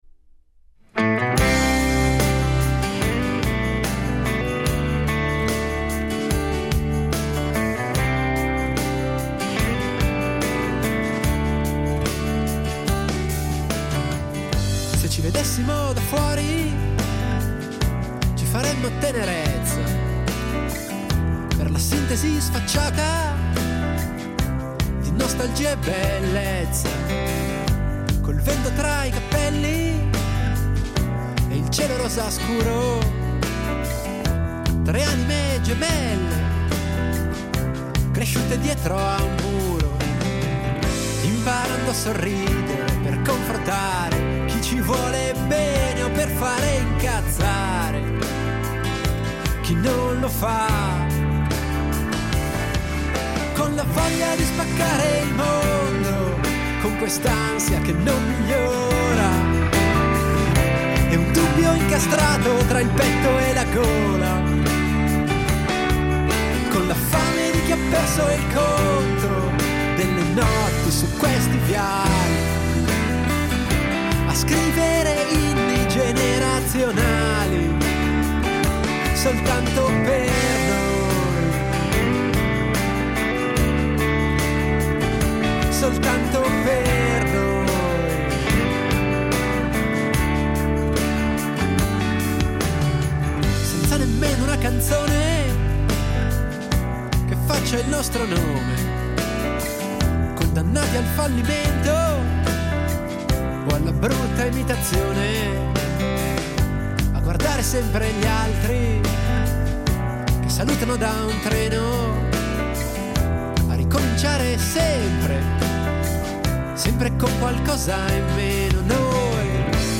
Incontro con il cantautore comasco